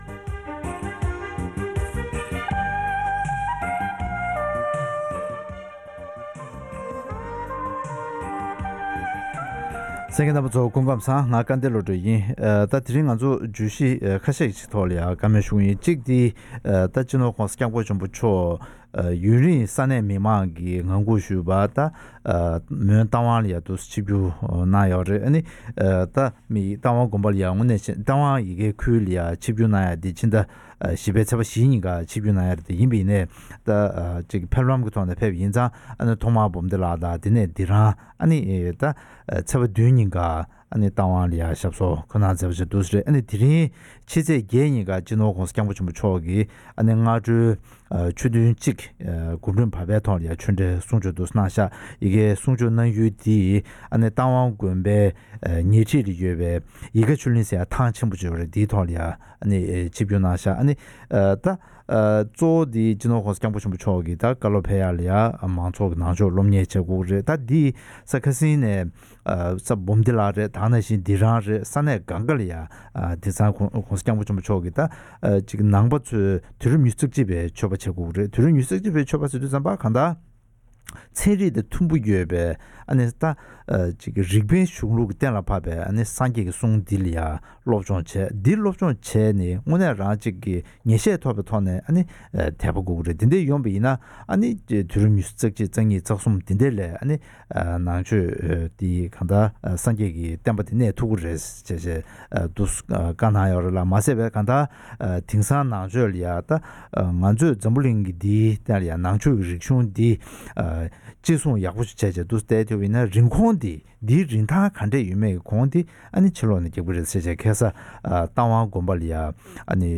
༄༅། །ཐེངས་འདིའི་གནད་དོན་གླེང་མོལ་གྱི་ལེ་ཚན་ནང་དུ།